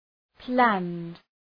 Προφορά
{plænd}